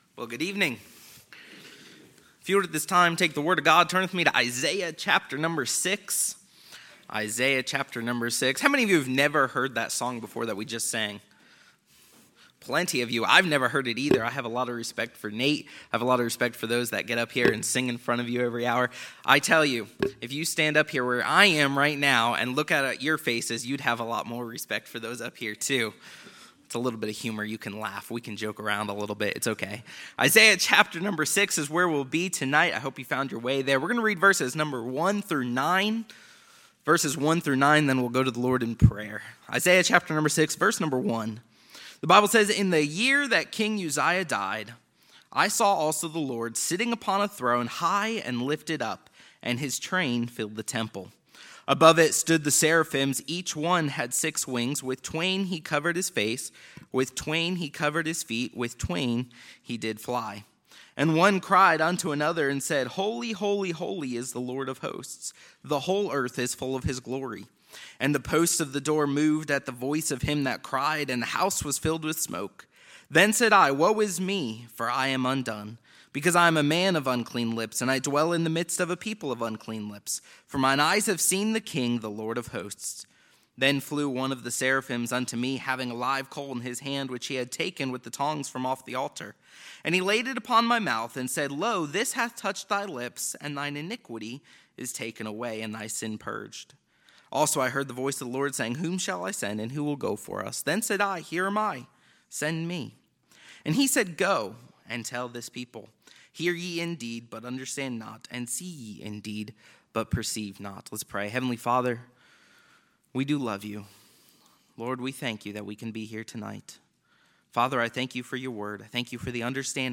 2022 Missions Conference , Sermons